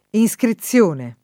inSkriZZL1ne]: var. letteraria in generale, ma più com. nel sign. geometrico: es. inscrizione d’un triangolo in una circonferenza